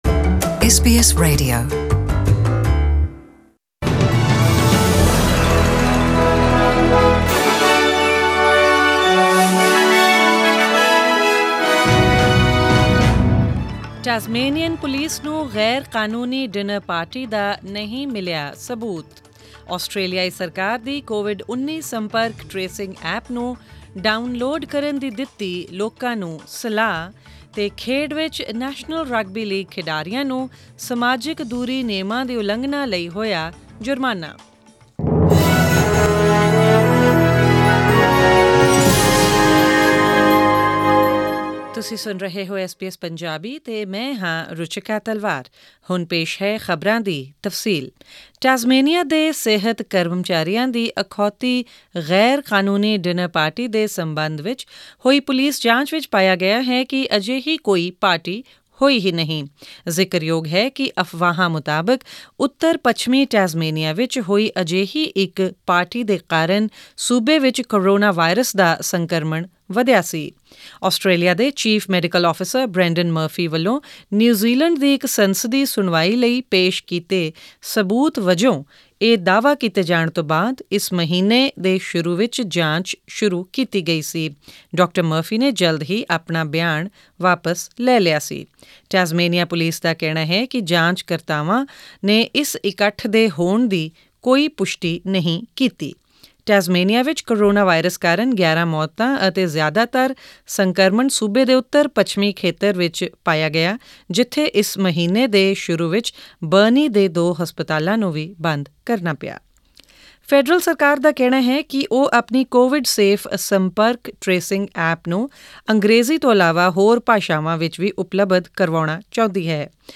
In this bulletin...